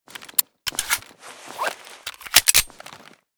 sig220_reload_empty.ogg.bak